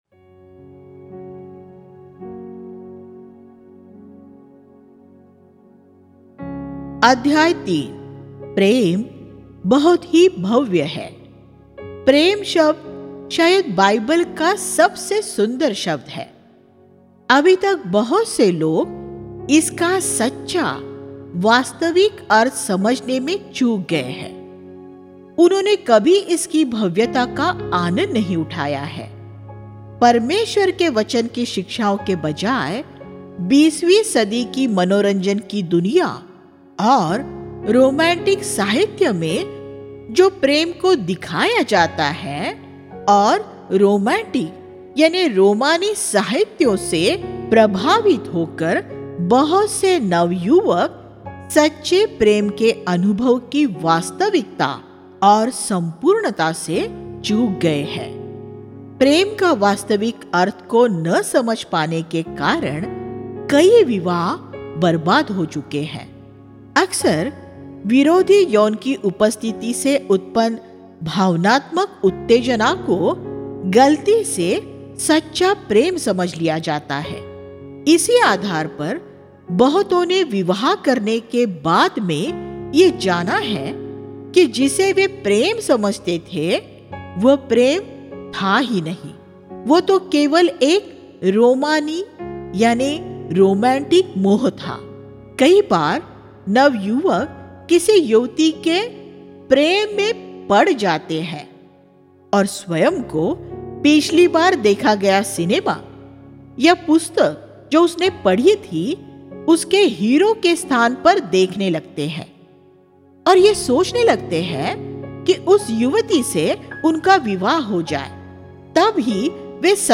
Love Is A Many-Splendoured Thing Sex, Love & Marriage Click here to View All Sermons इस शृंखला के उपदेश 1.